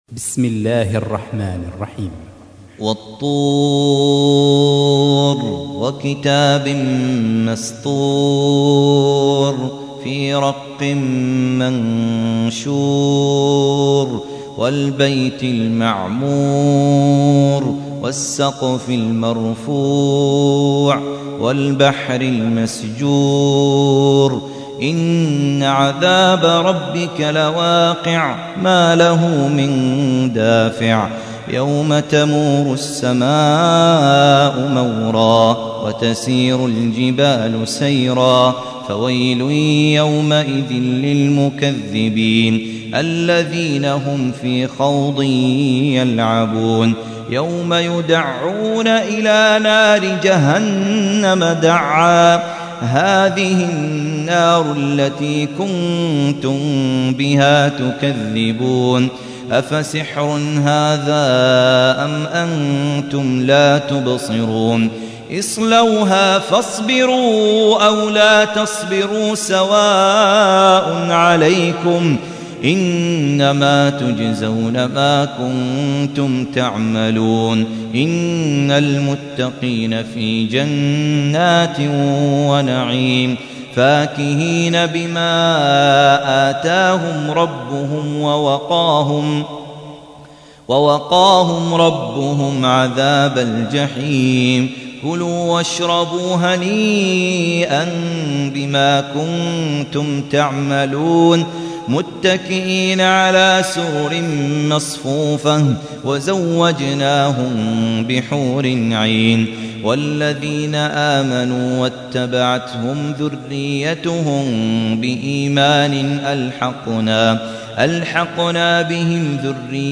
52. سورة الطور / القارئ